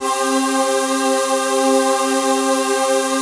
VOICEPAD18-LR.wav